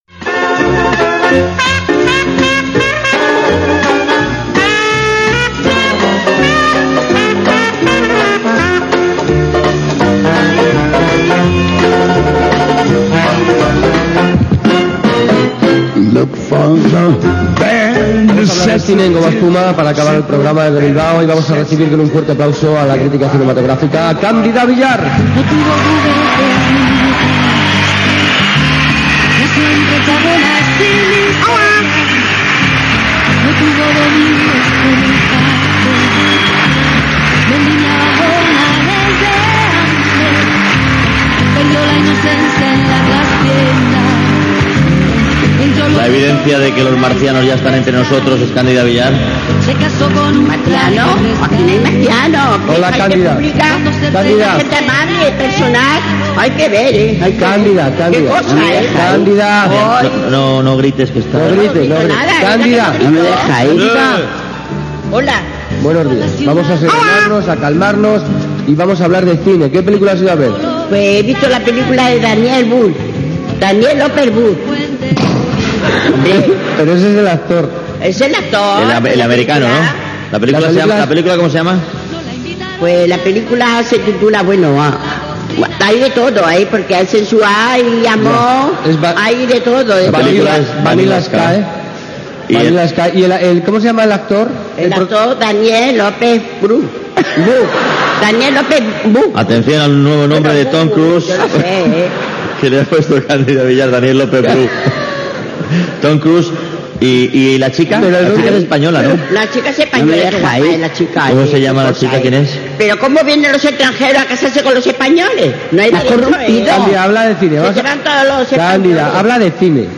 Fragment d'una emissió especial des de Bilbao amb la crítica cinematogràfica de la fictícia Cándida Villar.
Entreteniment